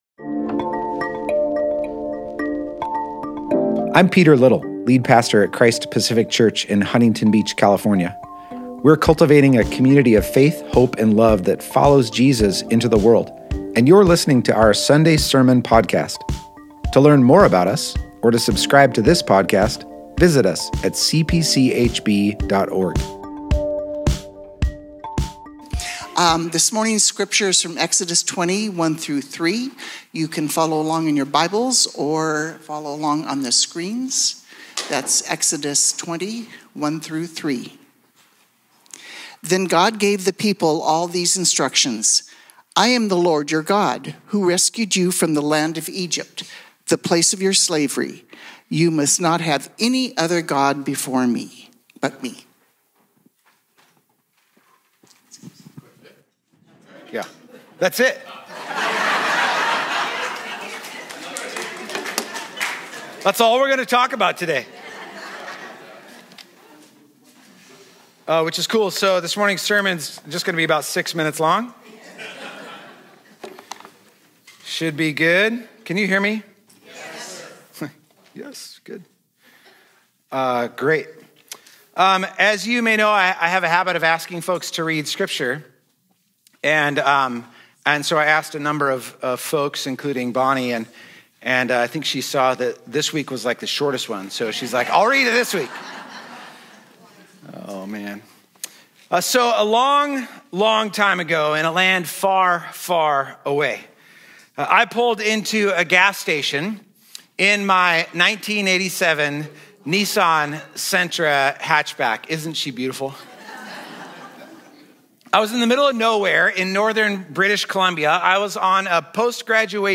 Join us this morning as we continue our sermon series, That You May Live: How the Ten Commandments Lead to Human Flourishing. We will be reading from Exodus 20:1-3 with the message ONE.